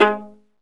Index of /90_sSampleCDs/Roland - String Master Series/STR_Viola Solo/STR_Vla2 % + dyn